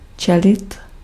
Ääntäminen
US : IPA : [ˈbreɪv]